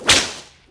Paradise/sound/weapons/whip.ogg at 355666e1a825252a4d08fa4e5cfced85e107ce39
whip.ogg